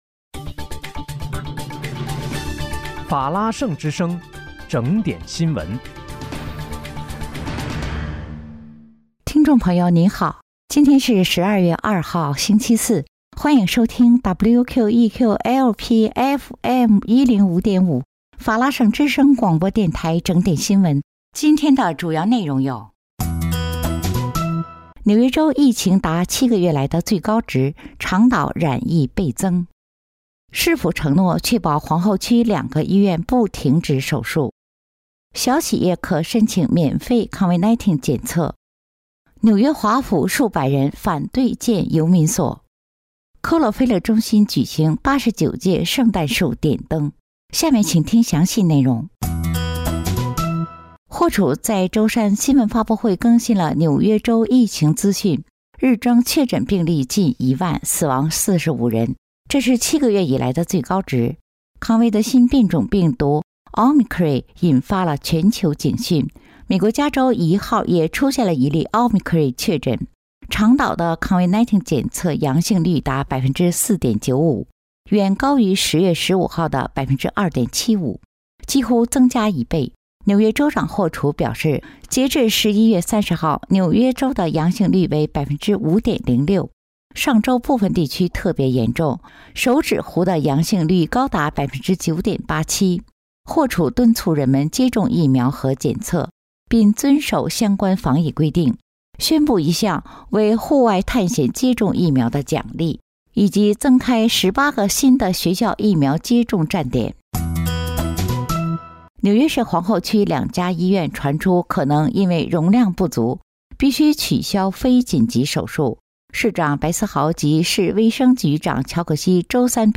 12月2日（星期四）纽约整点新闻